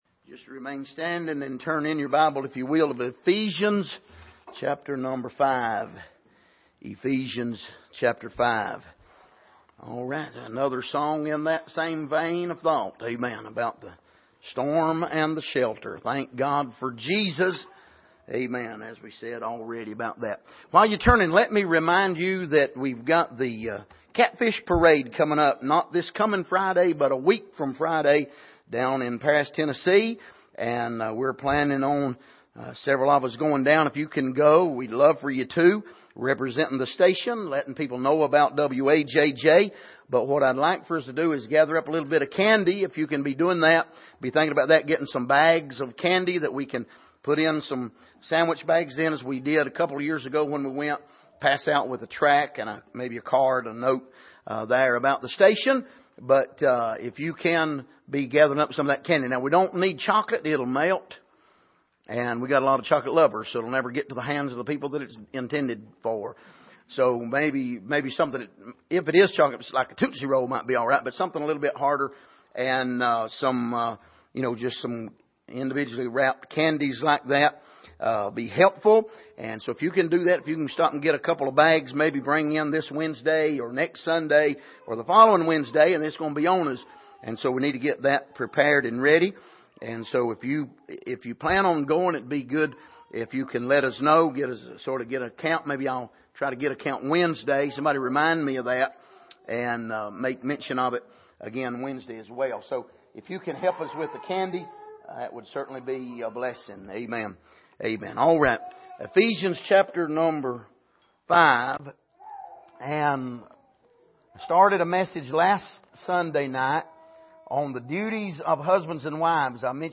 Service: Sunday Evening